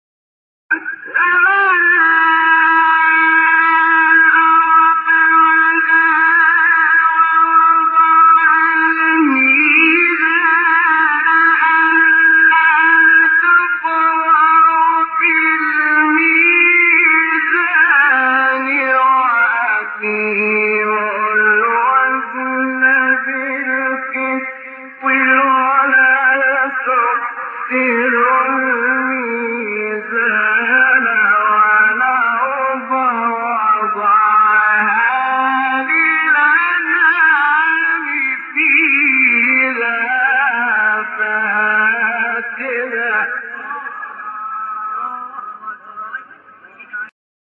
سوره : رحمان آیه: 8-9 استاد : شحات محمد انور مقام : سه گاه قبلی بعدی